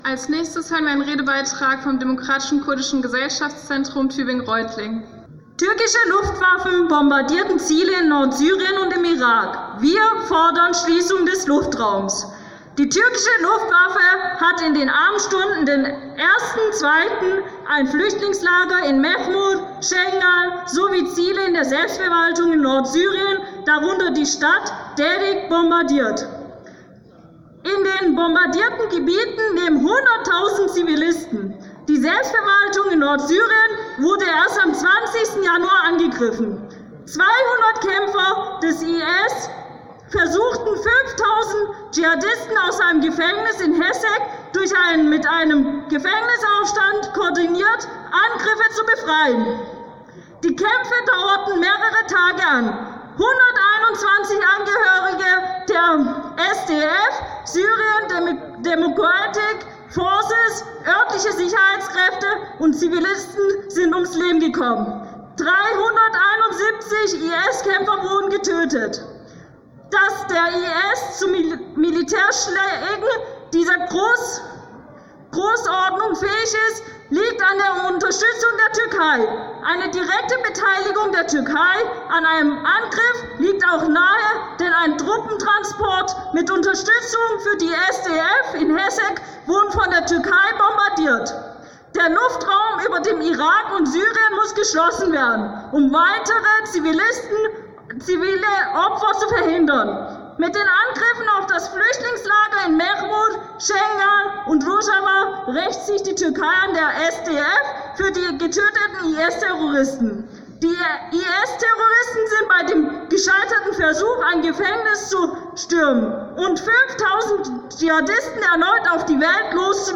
Redebeitrag des Demokratisch Kurdischen Zentrums Tübingen/Reutlingen